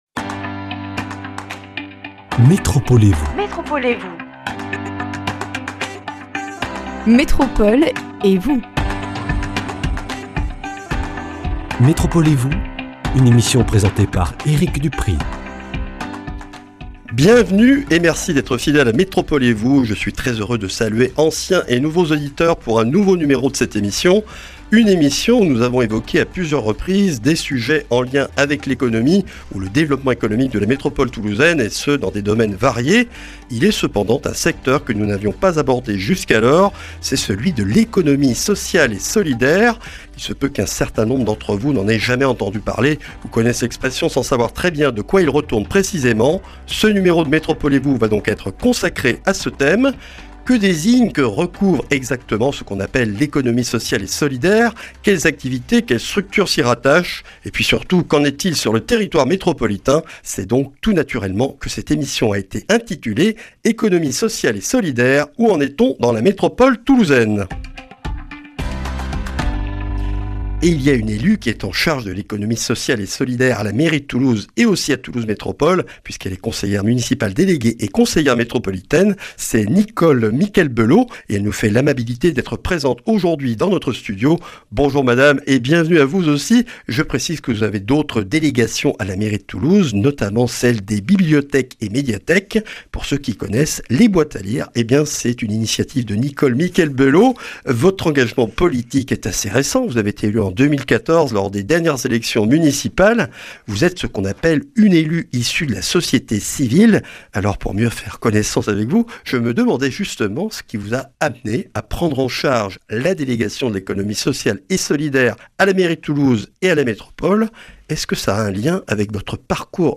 Nicole Miquel-Belaud, conseillère municipale déléguée à la Mairie de Toulouse et conseillère métropolitaine chargée de l’Économie sociale et solidaire, est l’invitée de cette émission. L’Économie sociale et solidaire se développe en France et dans la métropole toulousaine mais ses contours restent encore mal connus du grand public. Quelle est l’action de la métropole dans ce secteur ?